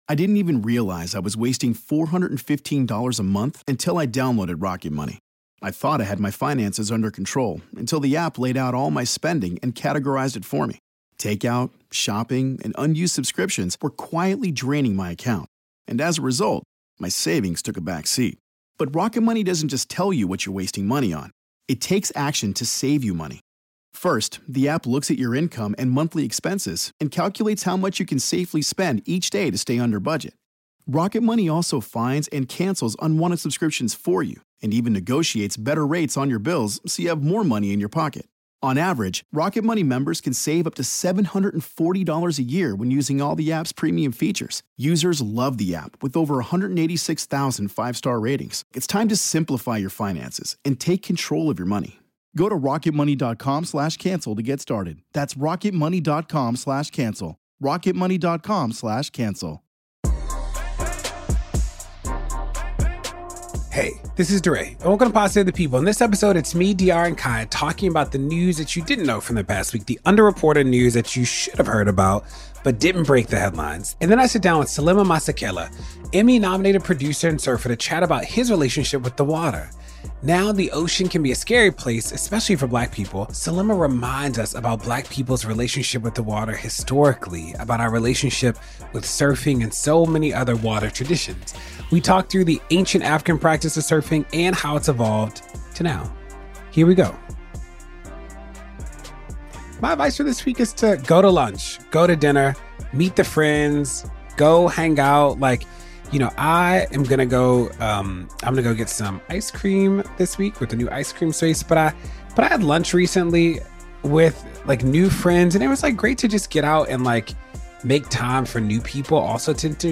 DeRay interviews Emmy-nominated producer & surfer Selema Masekela about his book Afro Surf, the new wave of activism centering Black surfers, and more.